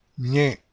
The letter Ñ, is one of “the new letters” for the English-Speaking people and you can try the phonetic association, which is very nasalized,  with sound “ni” in Onion.